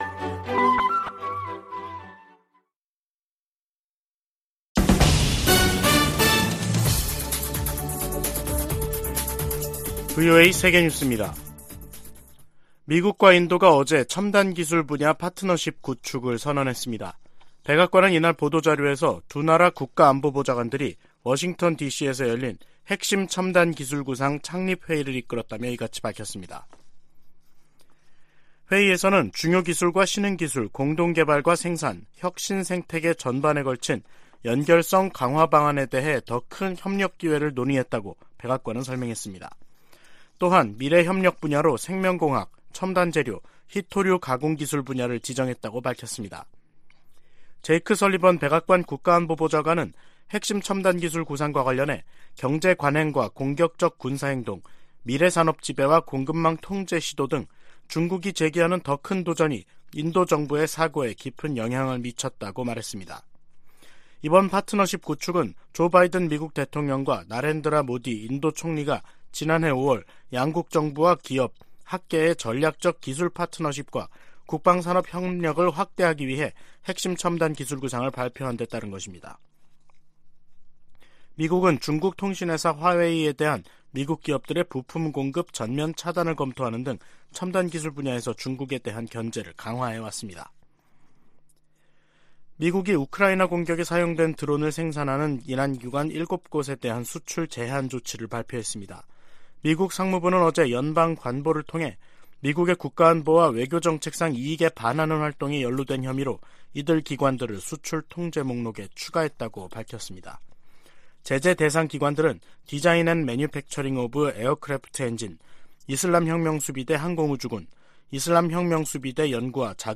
VOA 한국어 간판 뉴스 프로그램 '뉴스 투데이', 2023년 2월 1일 2부 방송입니다. 오는 3일 워싱턴에서 열릴 미-한 외교장관 회담에서는 북한의 도발 행위를 억제하는 중국의 역할을 끌어내기 위한 공조 외교를 펼 것이라는 관측이 나오고 있습니다. 미국 국무부가 한국에서 독자 핵 개발 지지 여론이 확대되는 것과 관련해, 핵무장 의지가 없다는 윤석열 정부의 약속을 상기시켰습니다.